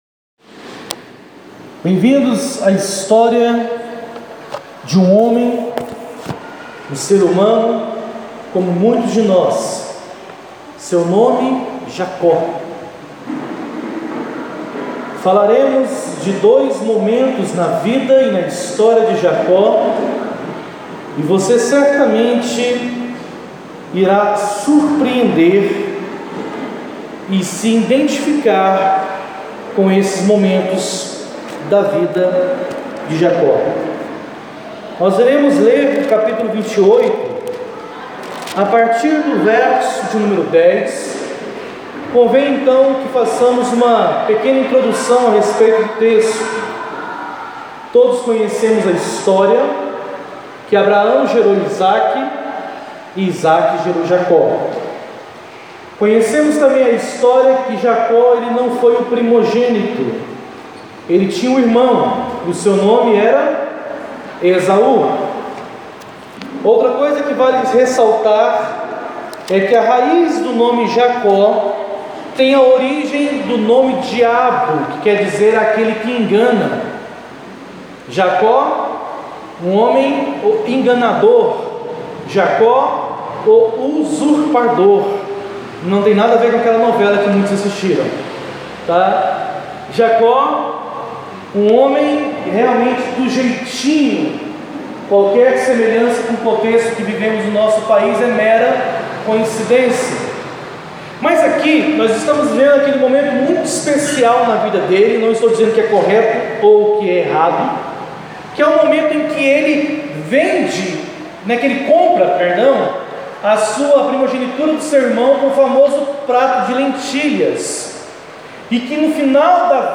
Igreja de Cristo Jardim Bela Vista